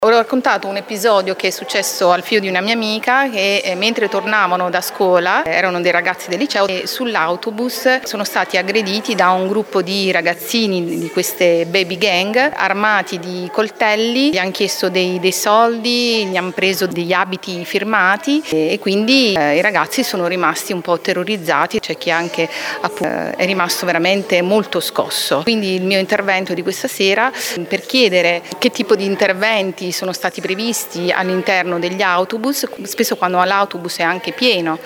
Tra le richieste dei genitori anche quella di aumentare i controlli sugli autobus: una mamma…
mamma-autobus.mp3